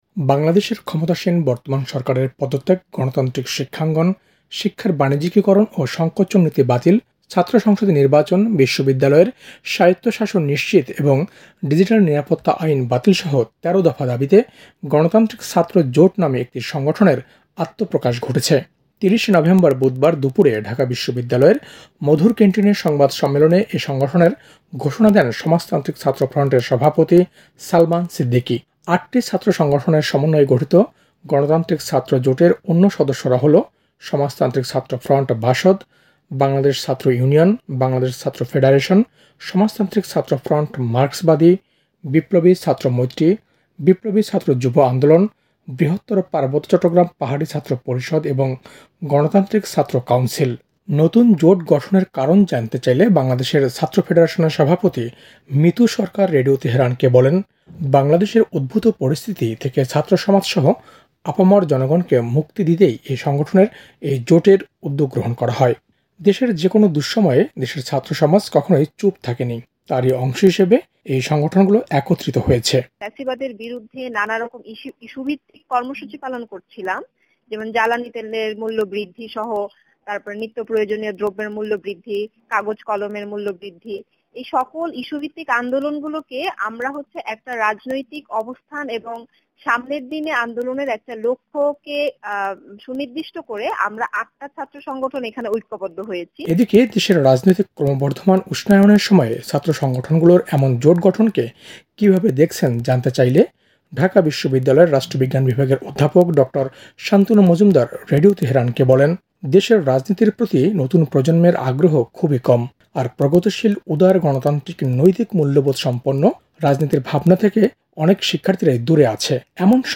১৩ দফা দাবিতে ‘গণতান্ত্রিক ছাত্র জোট’; রাজনৈতিক দায়িত্ববোধের প্রকাশ- মন্তব্য রাষ্ট্রবিজ্ঞানীর